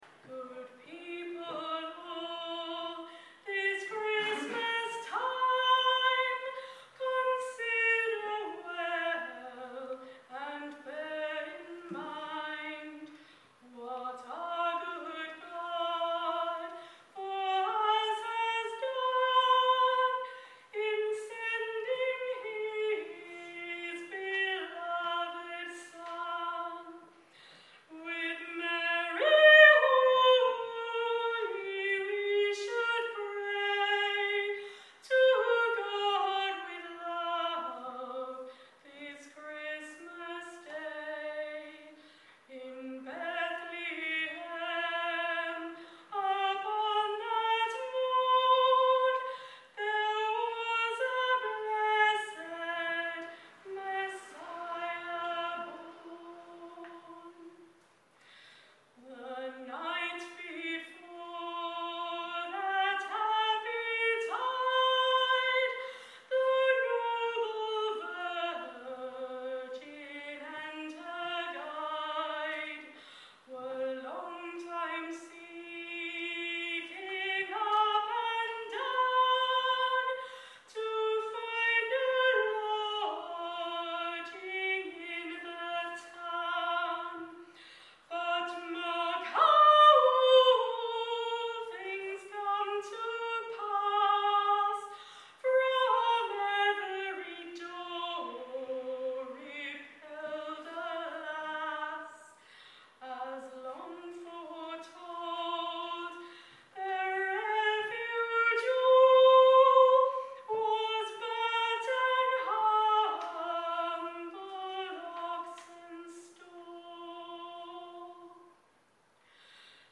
at the 2023 London Gallery Quire Christmas concert
This is an unaccompanied performance of this beautiful, traditional Irish carol.